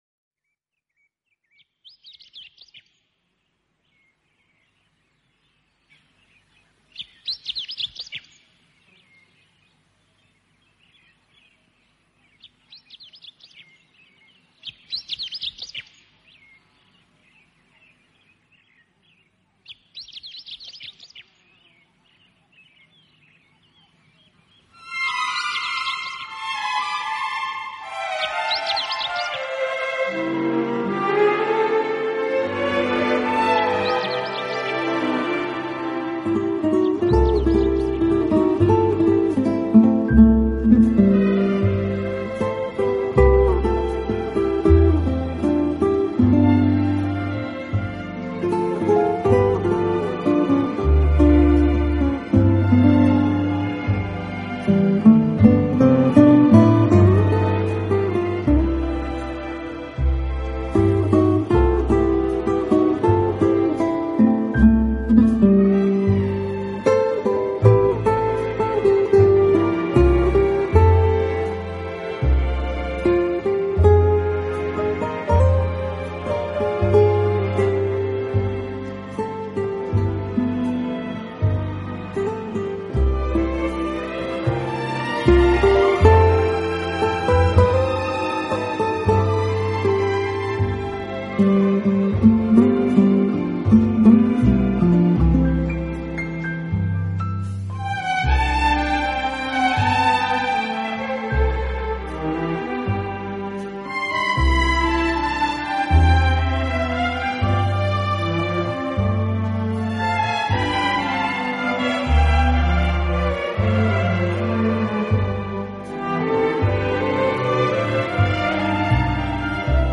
流水、雀鸟之声，能镇静人的情绪，松弛我们的身心，而且给人一种返回
从其不落俗套编曲，精简的乐器配置，使每首曲子都呈现出清新的自然气息。